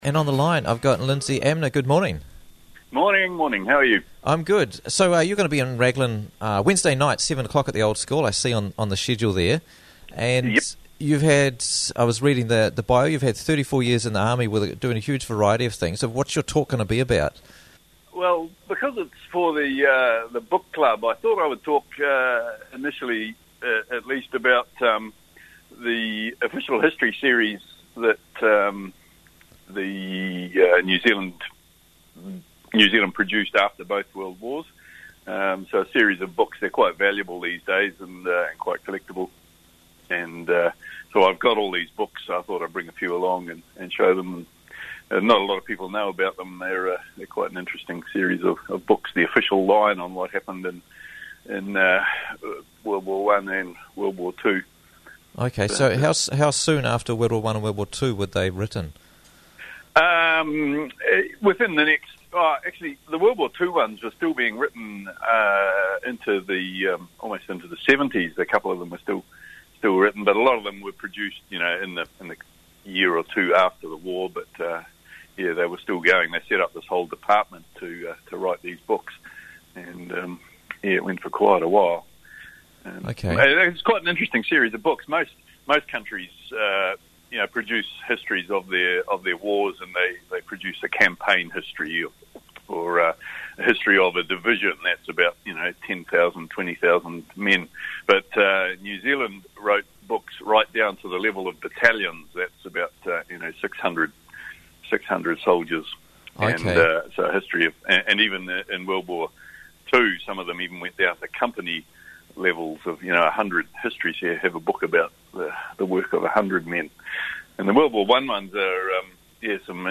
Quickly and easily listen to Interviews from the Raglan Morning Show for free!